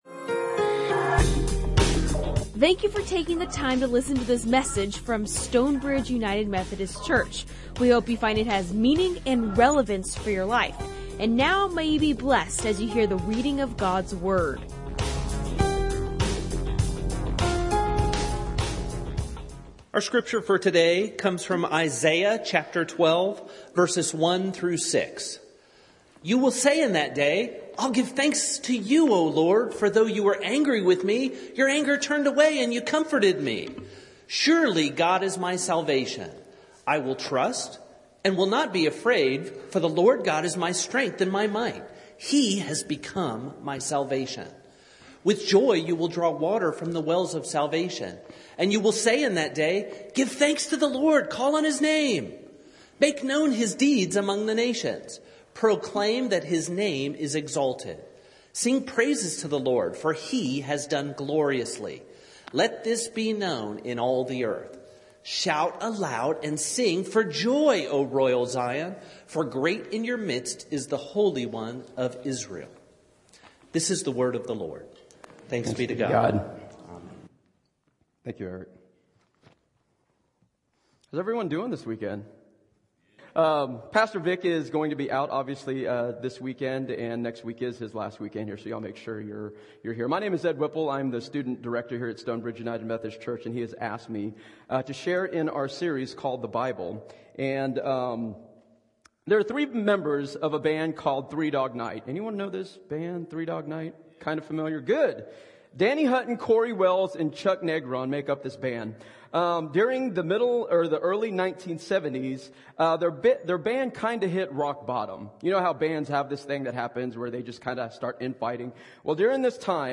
Recorded live at Stonebridge United Methodist Church in McKinney, TX.